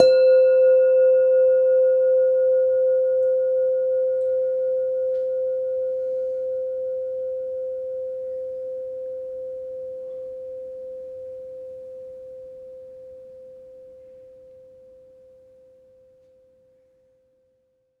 mono_bell_-2_C_18sec
bell bells bell-set bell-tone bong ding dong ping sound effect free sound royalty free Sound Effects